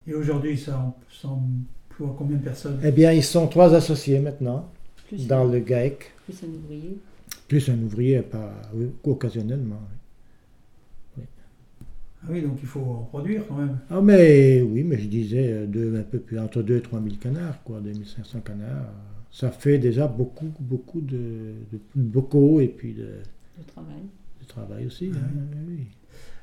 RADdO - Les employés par le Gaec La Ferme du Pas de l'Île - Document n°232520 - Témoignage